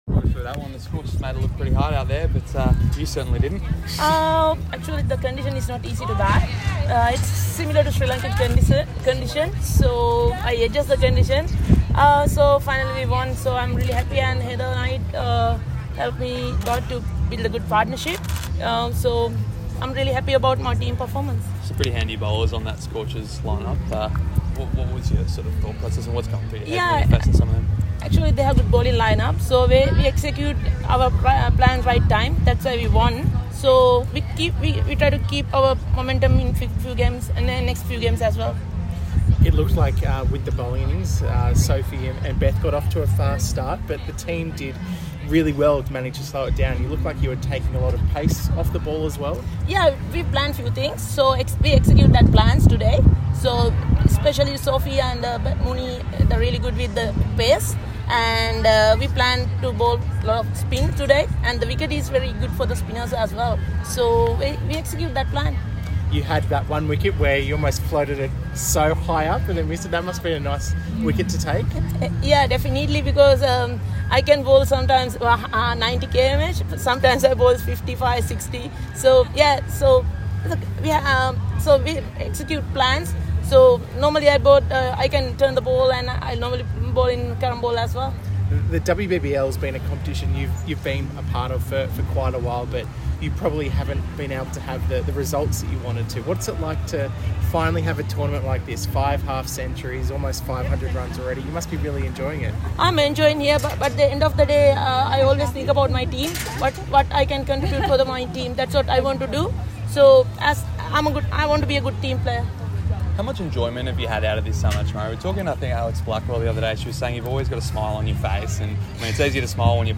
Chamari Athapaththu shines as Player of the Match with 77* off 53 balls! Catch her exclusive interview following Sydney Thunder Women's commanding 9-wicket victory (WBBL/CA)